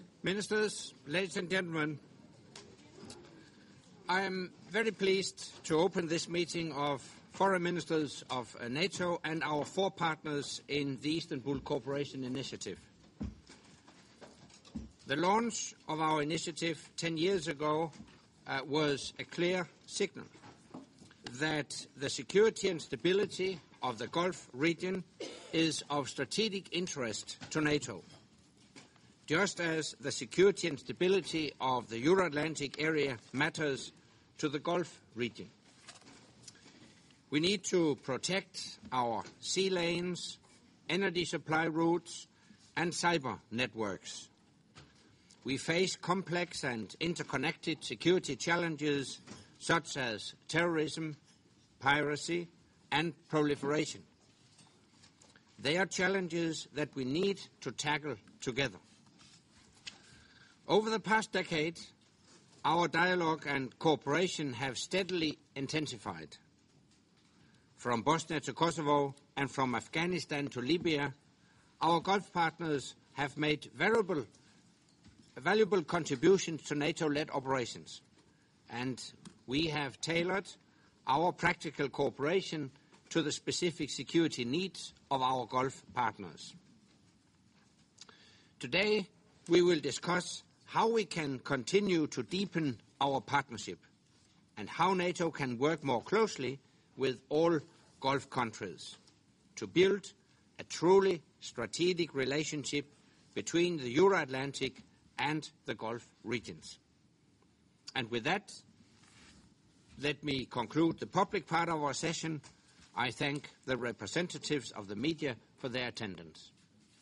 Opening remarks by NATO Secretary General Anders Fogh Rasmussen at the meeting of the North Atlantic Council with non-NATO ISAF Contributing Nations at the level of Foreign Affairs Ministers